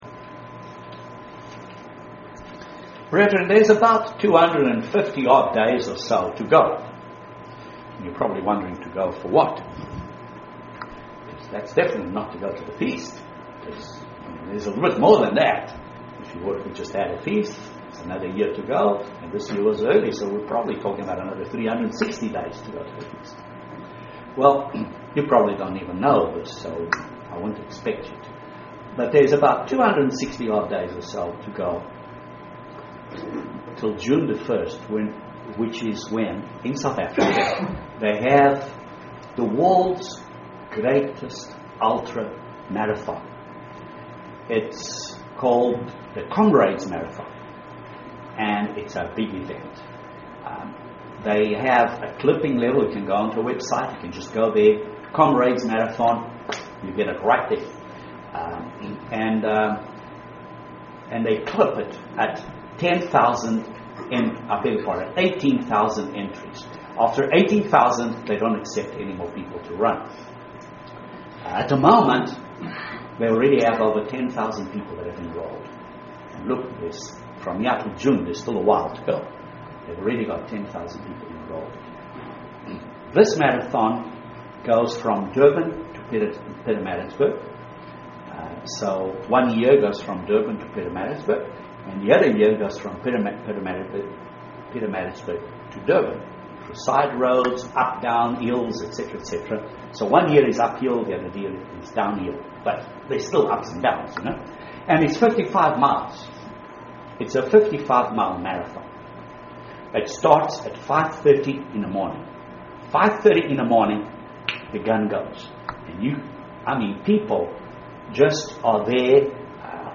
Three points to remember to use God's Power of encouragement UCG Sermon Transcript This transcript was generated by AI and may contain errors.